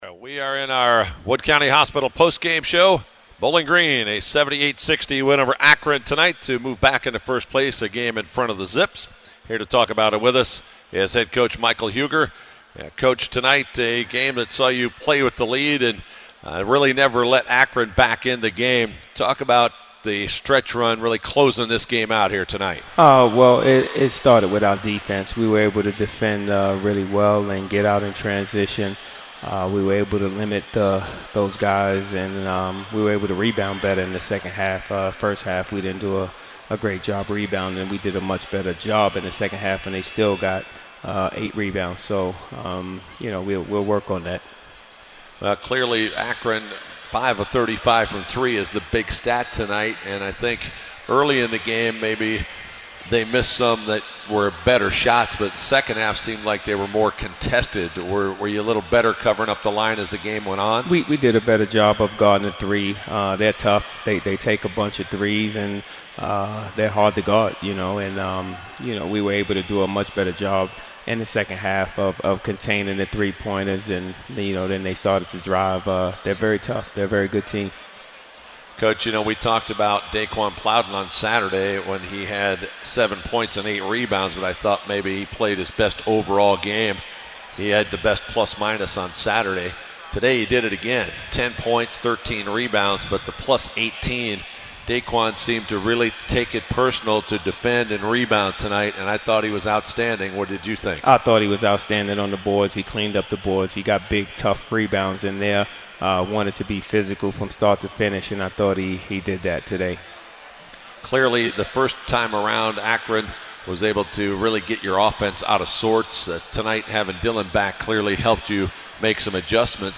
Post-Game Audio: